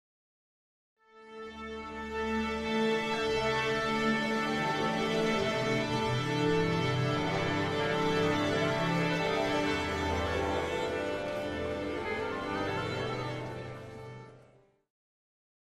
Orchestra
Orchestra Tuning Ambience 3 - Short Symphonic - Musical